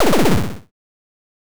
8bit_FX_Shot_02_03.wav